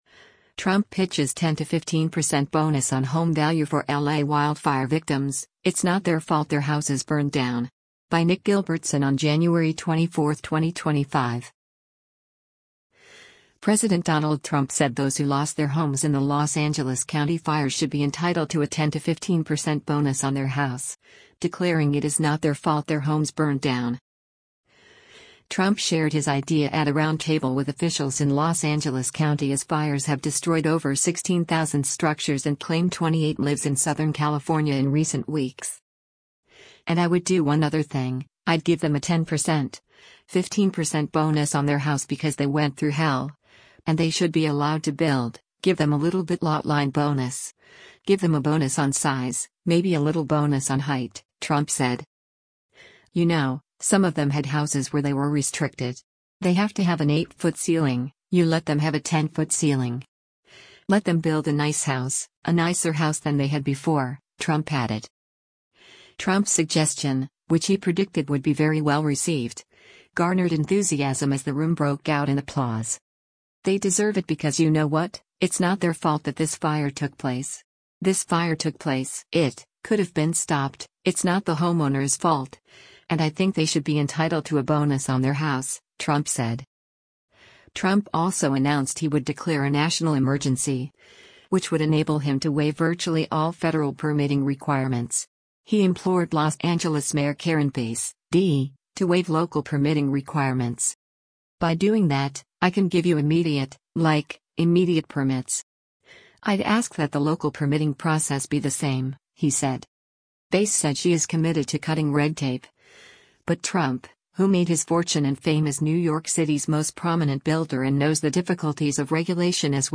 Trump shared his idea at a roundtable with officials in Los Angeles County as fires have destroyed over 16,000 structures and claimed 28 lives in Southern California in recent weeks.
Trump’s suggestion, which he predicted would be “very well-received,” garnered enthusiasm as the room broke out in applause.